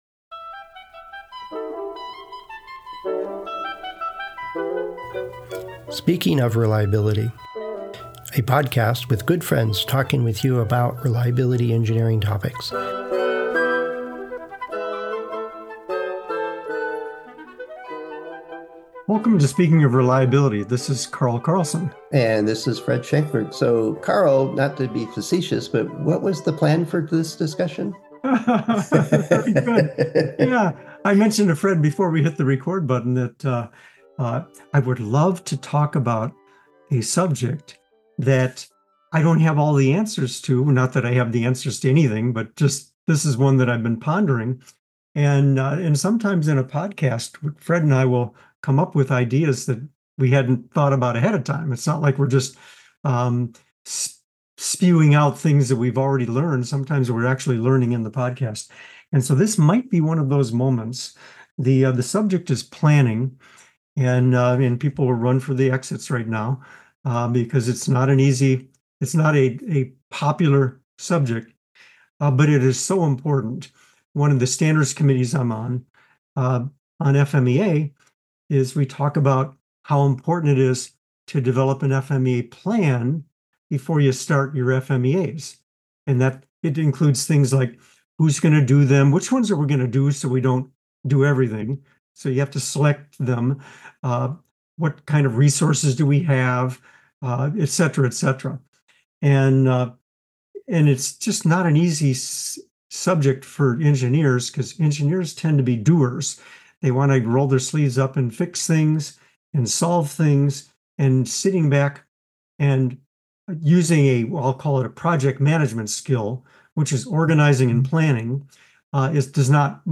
Friends Discussing Reliability Engineering Topics